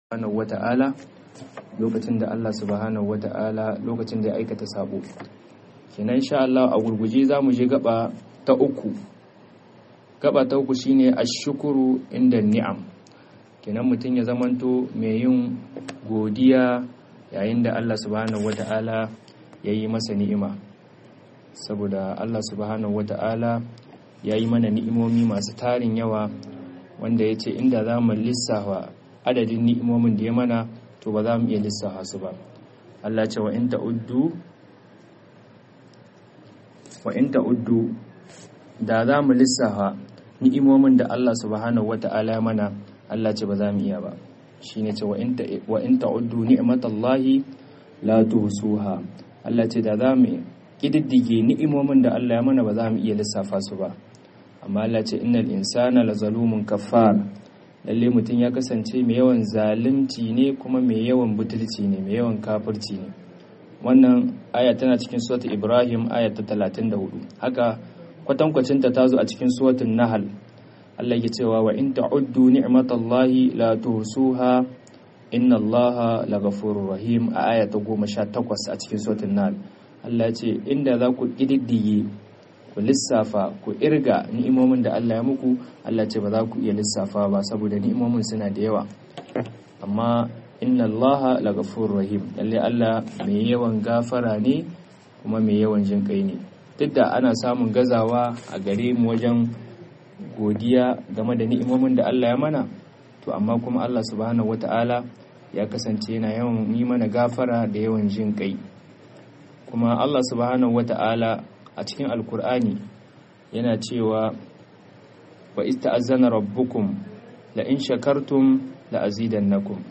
2025-10-02_21'10'07' godiya akan ni'imar Allah - MUHADARORI